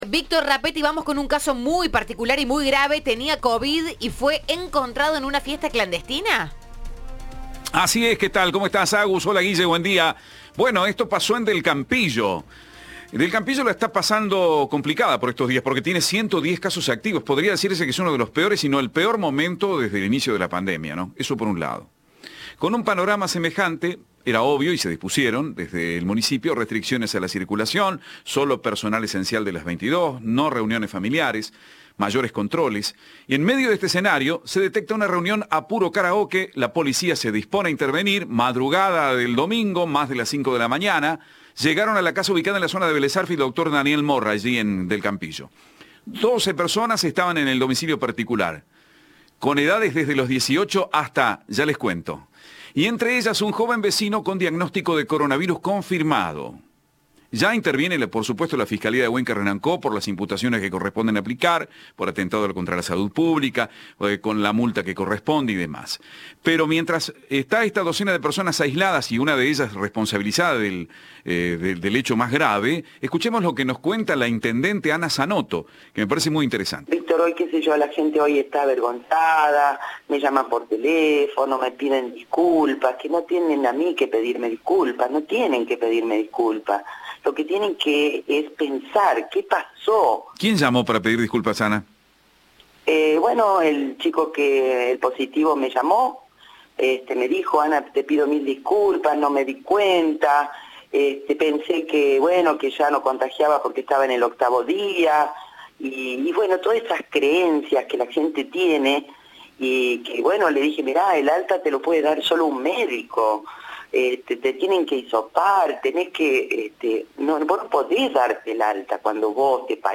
En diálogo con Cadena 3, la intendente Ana María Zanotto dijo que la persona en cuestión la llamó para pedirle disculpas.
Informe